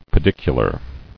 [pe·dic·u·lar]